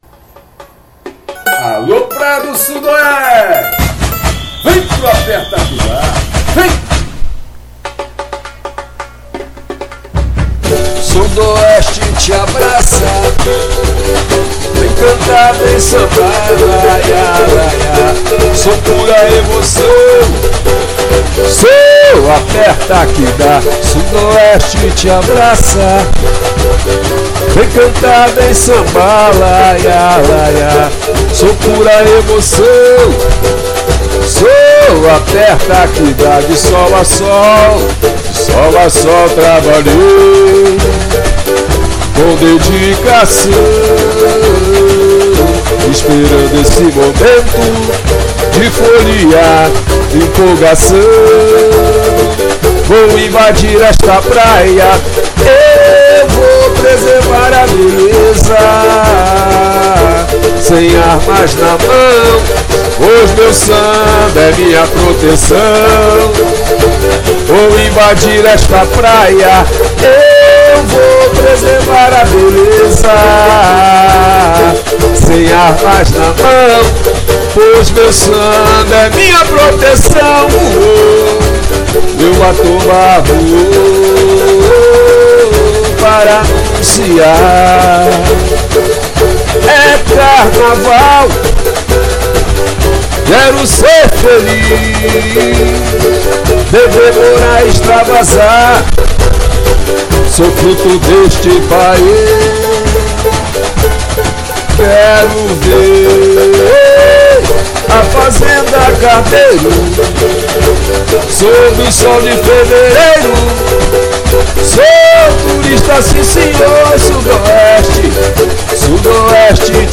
Samba-Enredo
Carnaval 2020